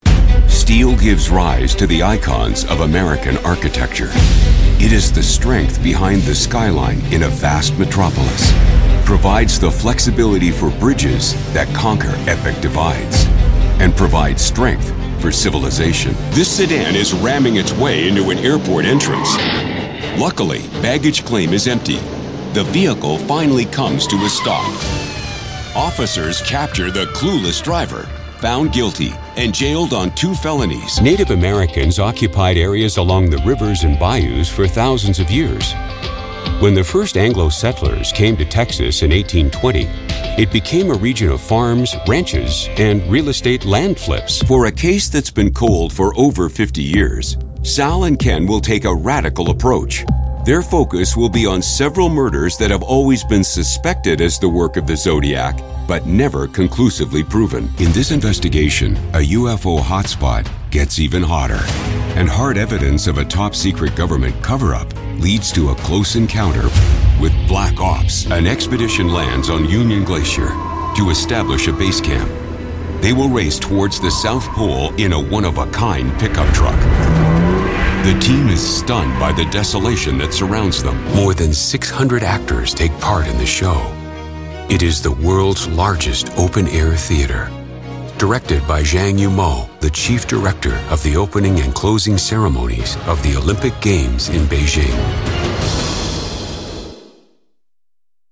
English (Canadian)
Narration
Sennheiser MKH 416 microphone
Custom built voice booth
BaritoneBassDeep
ConfidentGroundedSeriousAuthoritativeConversationalCorporateExperienced